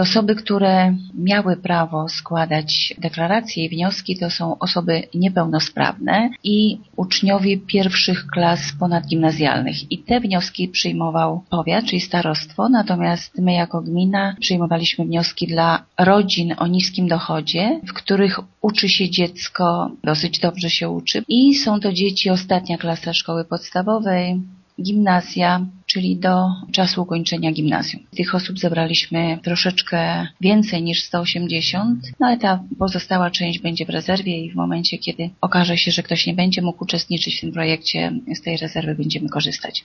„W sumie do rozdysponowania będzie 180 zestawów komputerowych” – mówi burmistrz Zwolenia Bogusława Jaworska: